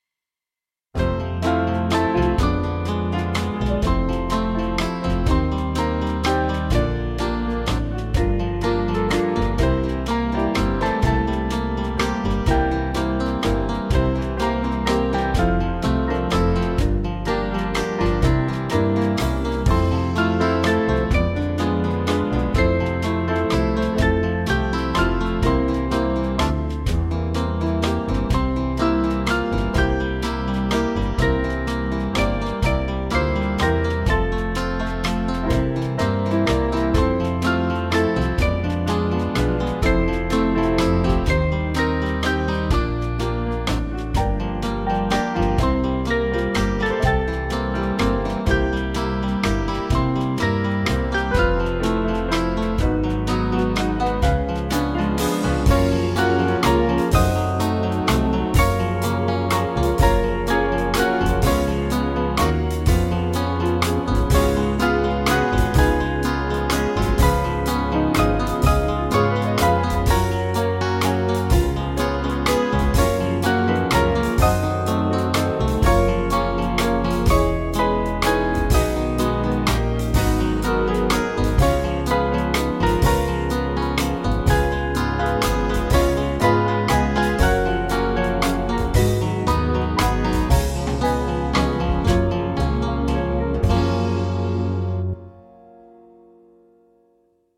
Small Band
(CM)   2/F-Gb